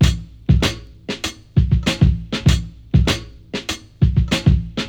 • 98 Bpm Drum Loop B Key.wav
Free breakbeat sample - kick tuned to the B note. Loudest frequency: 1032Hz
98-bpm-drum-loop-b-key-rYF.wav